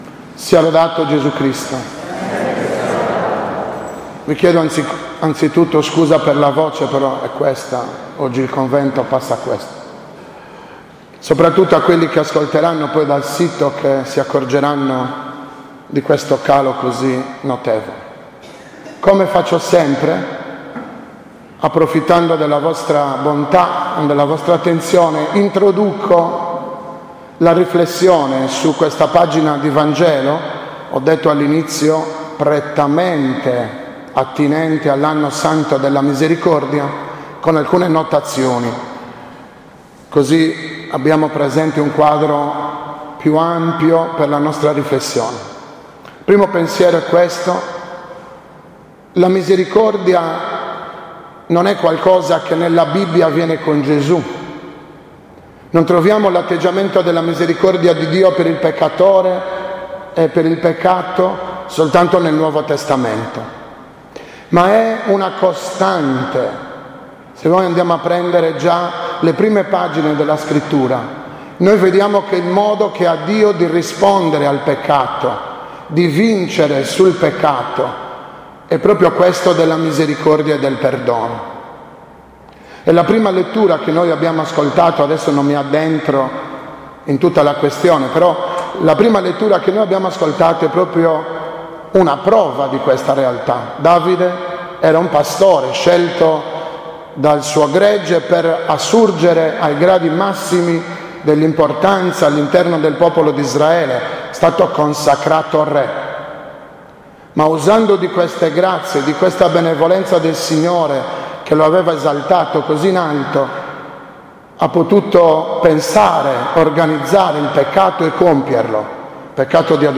12.06.2016 – OMELIA DELLA XI DOMENICA DEL TEMPO ORDINARIO